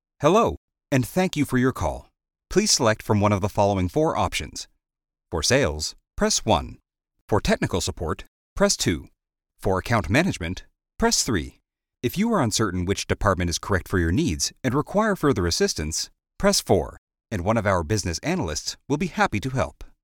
Male
I provide a warm, deep range with various styles.
Phone Greetings / On Hold
Business On Hold Message
Words that describe my voice are Deep, Warm, Narrative.
All our voice actors have professional broadcast quality recording studios.